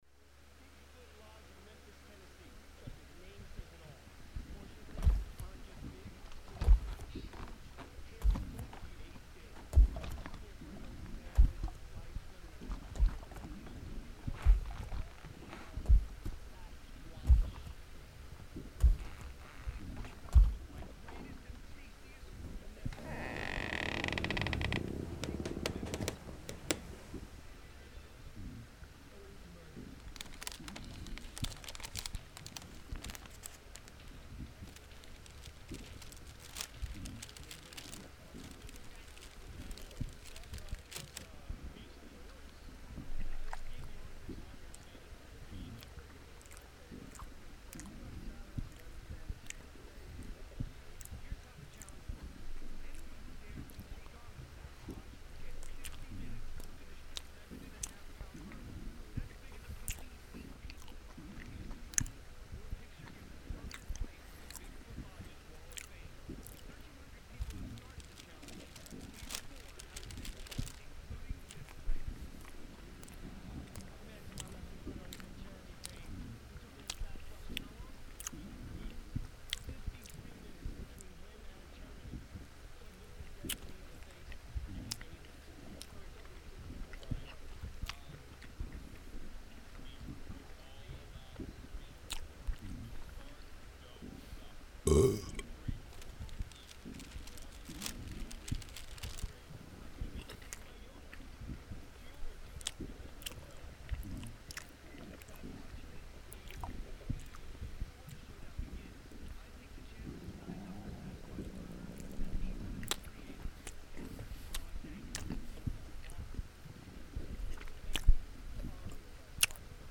This is a compressed, lower-quality version ~